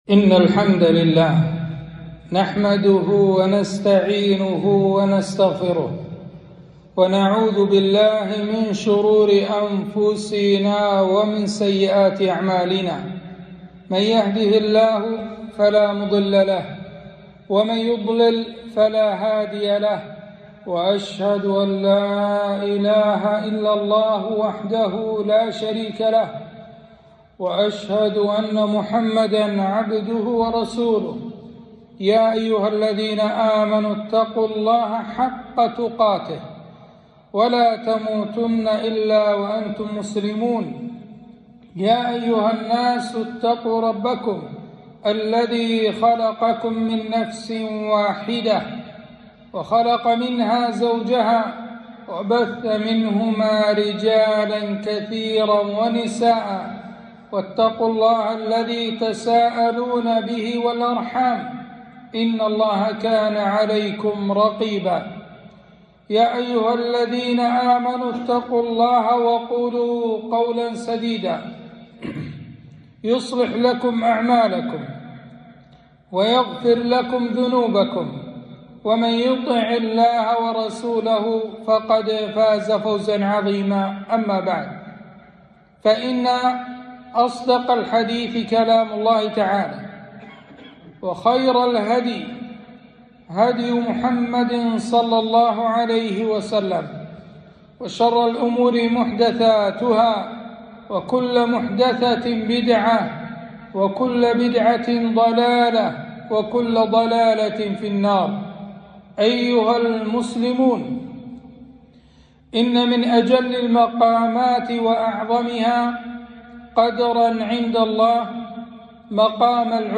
خطبة - أهمية تحقيق العبودية